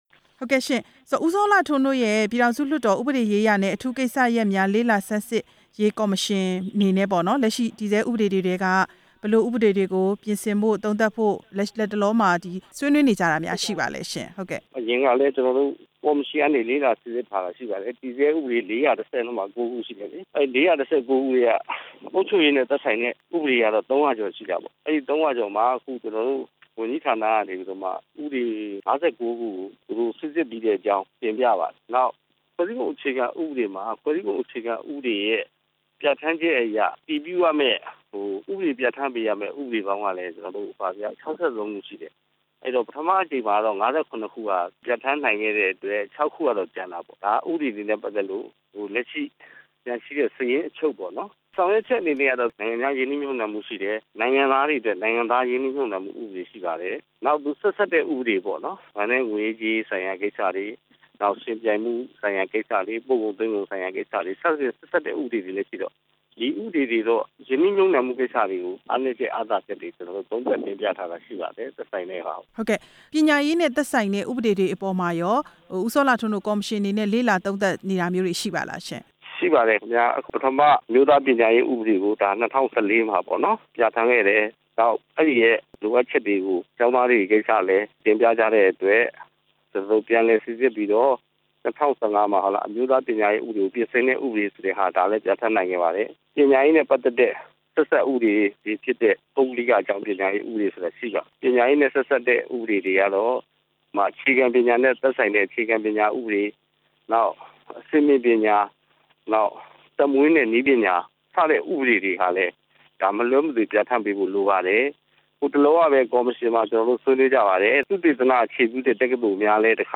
အထူးကိစ္စရပ်များ လေ့လာသုံးသပ်ရေးကော်မတီအဖွဲ့ဝင် ဦးစောလှထွန်းနဲ့ မေးမြန်းချက်